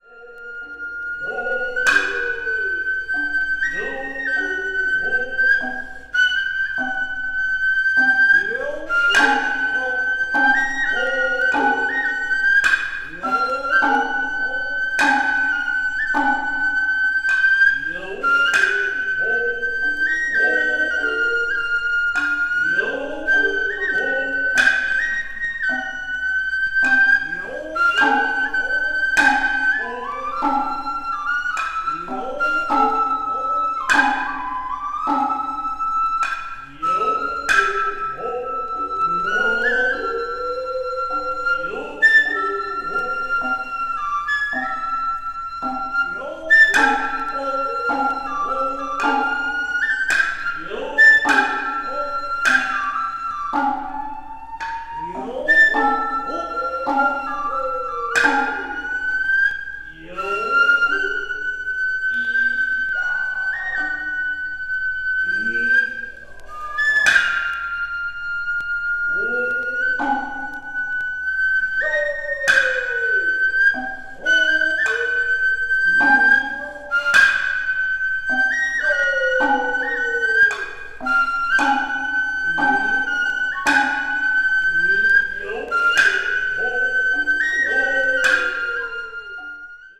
media : EX/EX,EX/EX(わずかにチリノイズが入る箇所あり)
east asia   ethnic music   japan   oriental   traditional